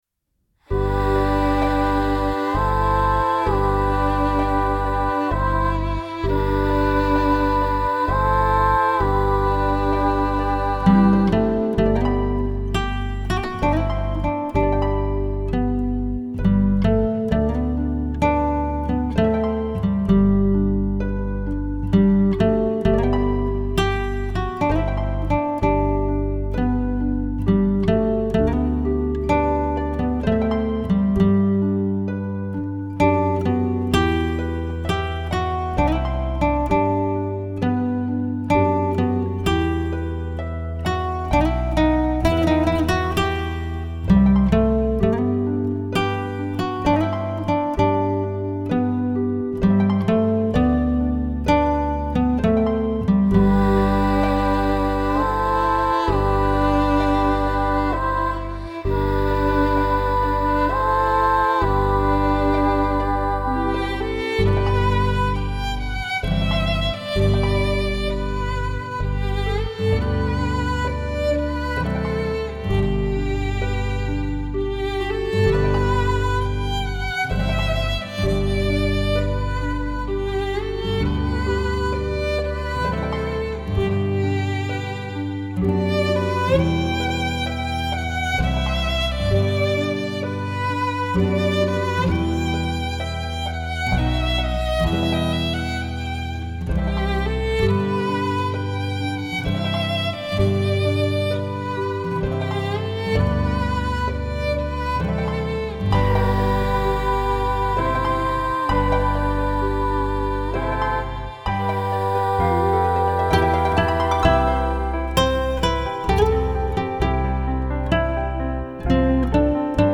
整张专辑散发着自然生动的音乐性格，
每一个音符都充满写意和舒心，
吉他和其他乐器演奏挥洒自如，
层次感分明。